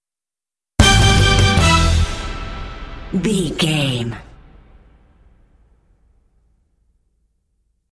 Epic / Action
Fast paced
Aeolian/Minor
foreboding
suspense
strings
drums
bass guitar
orchestral
symphonic rock